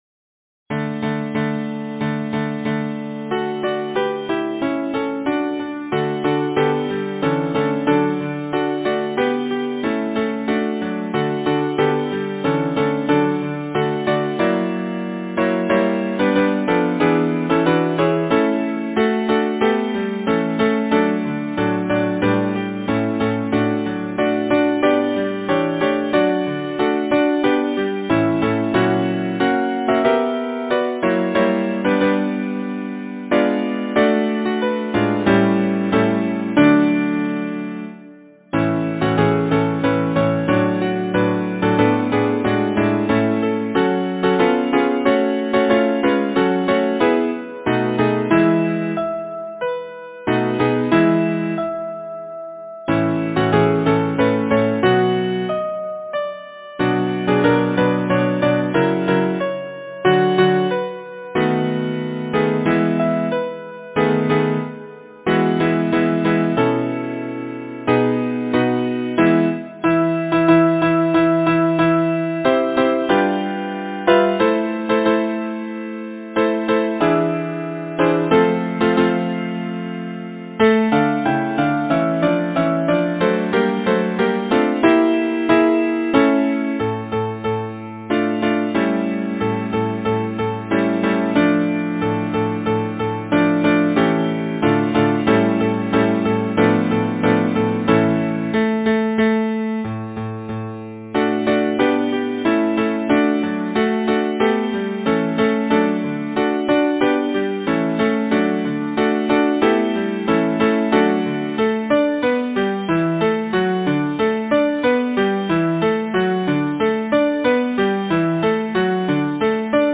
Title: The Bells Composer: Henry Lahee Lyricist: Edgar Allan Poe Number of voices: 4vv Voicing: SATB, divisi Genre: Secular, Partsong
Language: English Instruments: A cappella